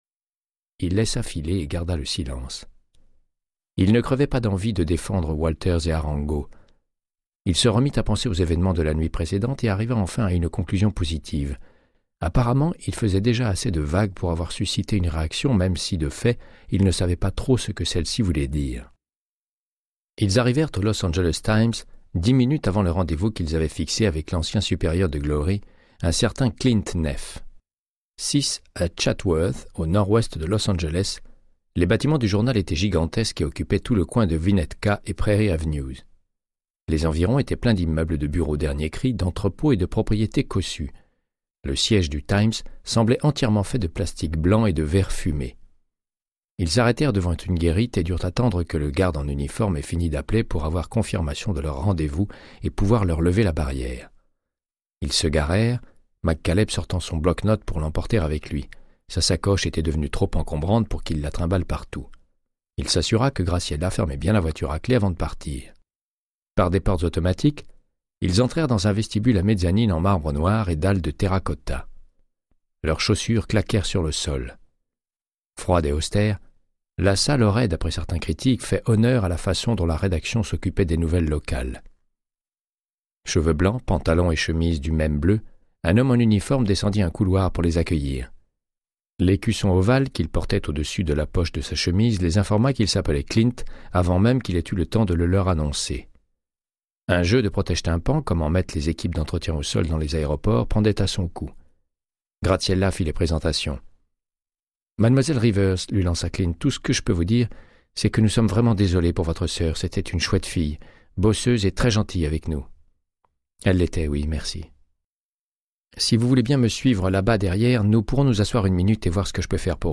Audiobook = Créance de sang, de Michael Connelly - 110